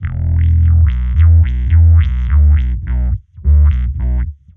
Index of /musicradar/dub-designer-samples/105bpm/Bass
DD_JBassFX_105E.wav